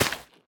minecraft / sounds / dig / wet_grass3.ogg
wet_grass3.ogg